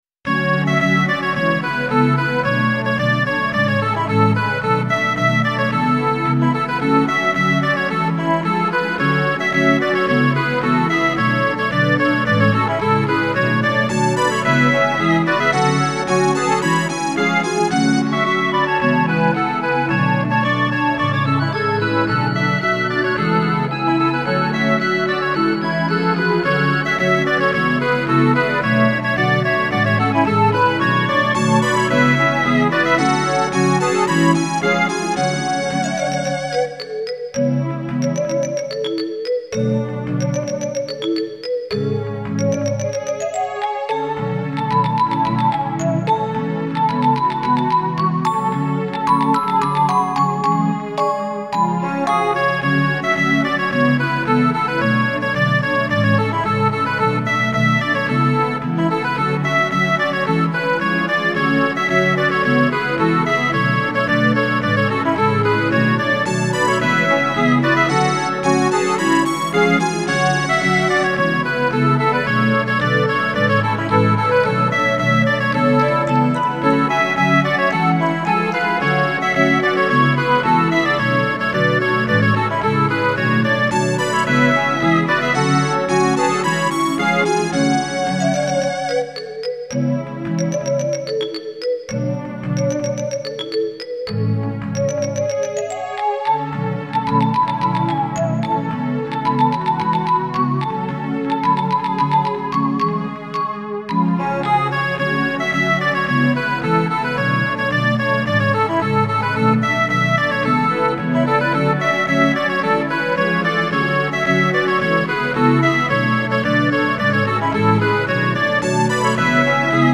Game Music Style
ゲームミュージック風。勇壮な曲が多めです。
前進の雰囲気。イングリッシュホルンがメイン。